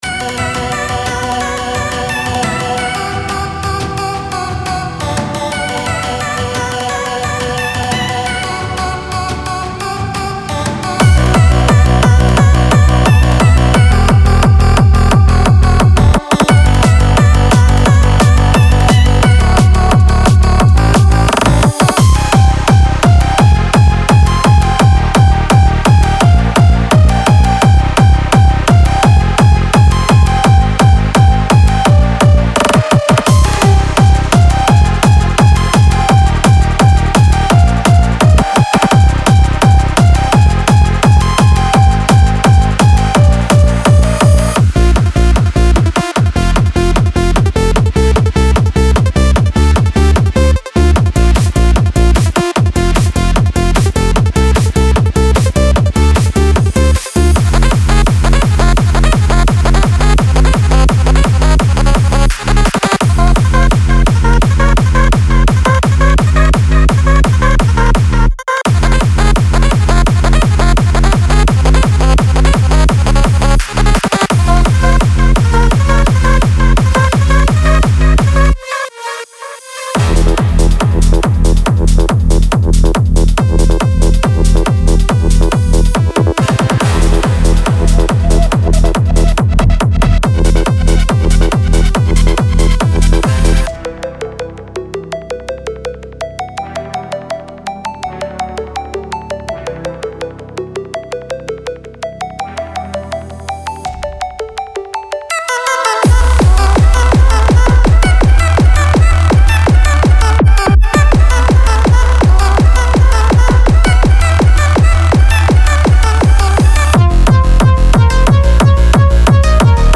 Melodic Techno Techno